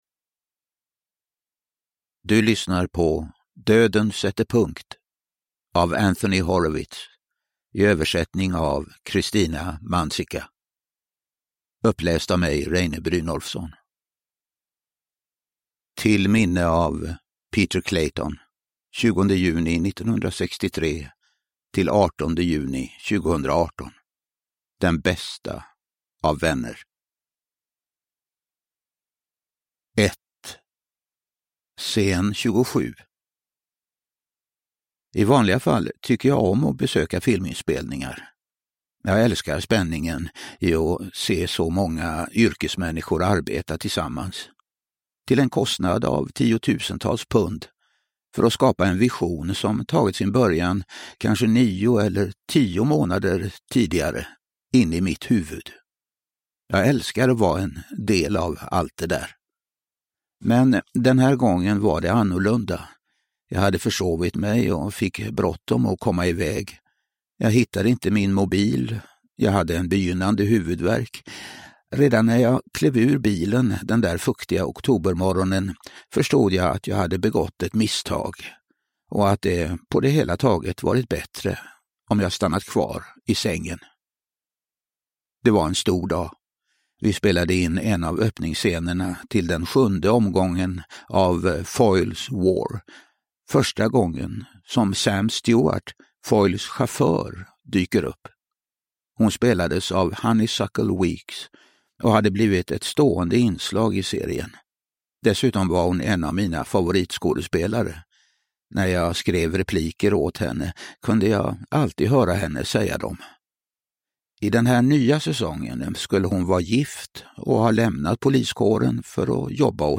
Döden sätter punkt – Ljudbok
Uppläsare: Reine Brynolfsson